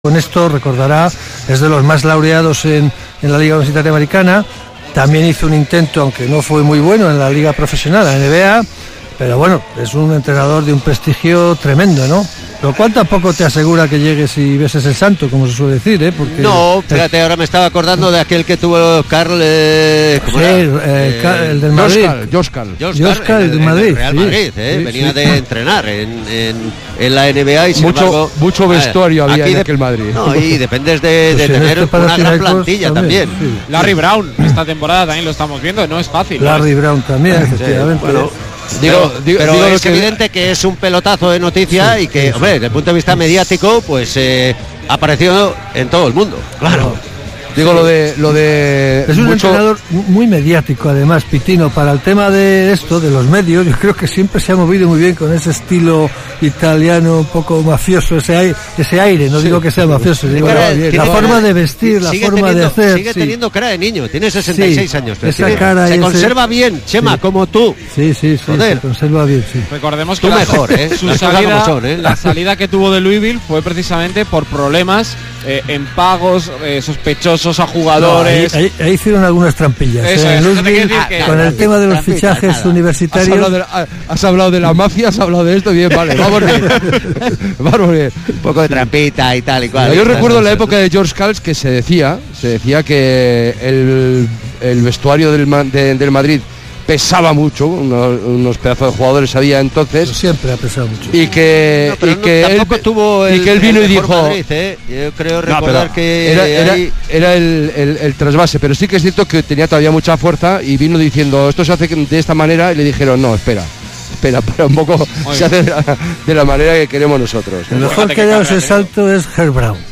Kirolbet Baskonia-Barça jornada 14 euroleague 2018-19 retransmisión Radio Vitoria (solo primera parte)